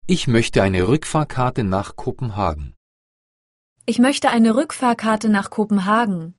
ايش موشتا اينا روكفاكرتا نا كوبنهاكن